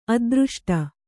♪ adřṣṭa